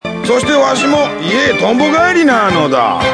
パパの声２ (mp3形式 25KB)
ぱ、パパの声が変わっているのだ！！
小倉久寛さんでありました。